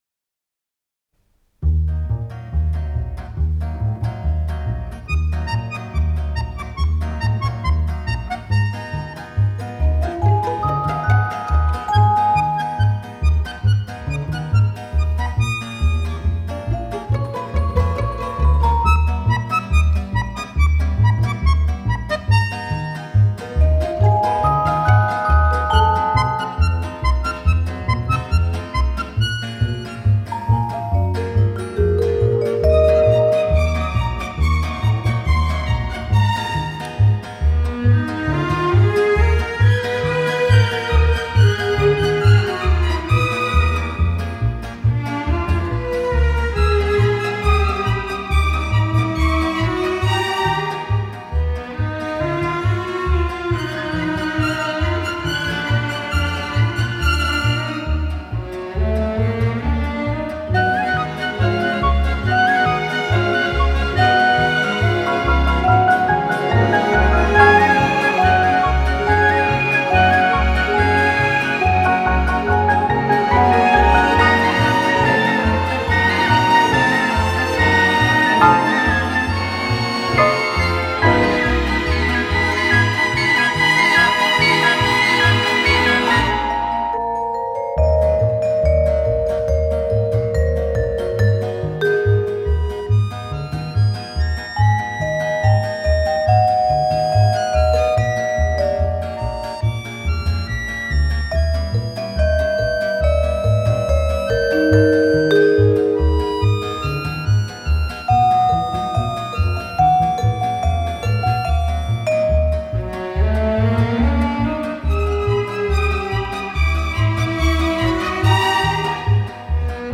оркестровым произведениям